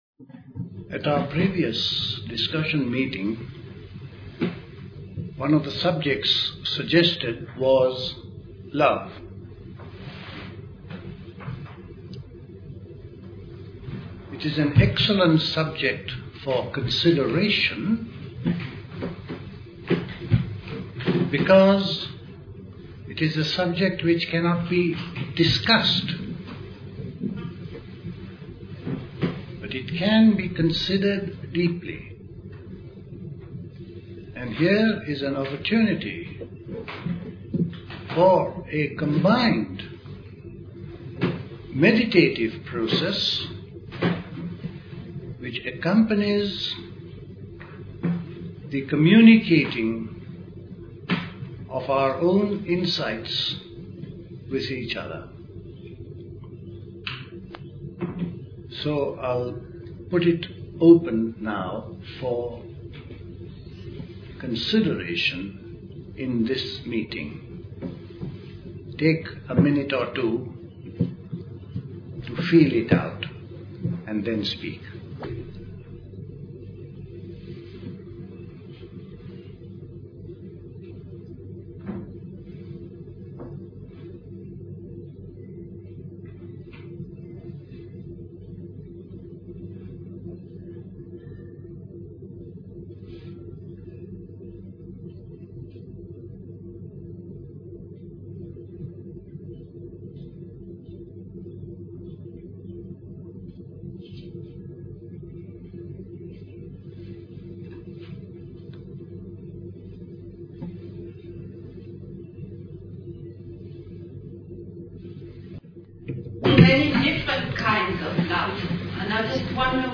Recorded at the 1974 Park Place Summer School.